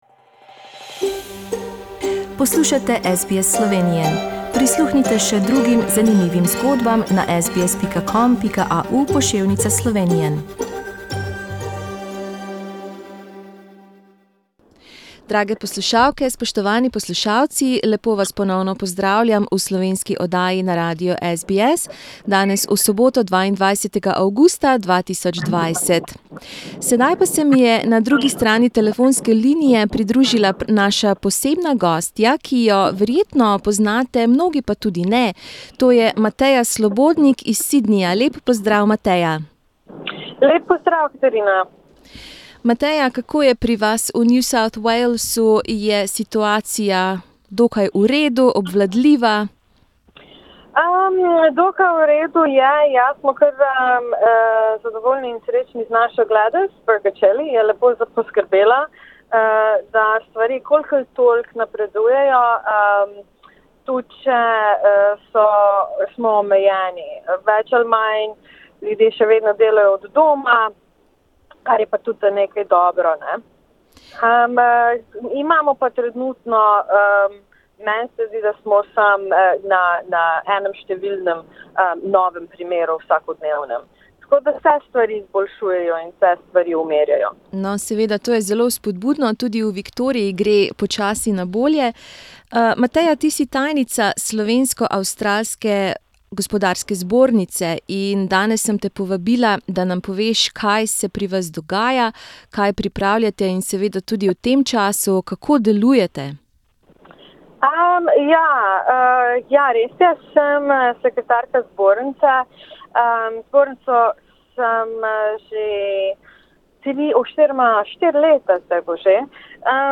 Belokranjka z avstralskim naglasom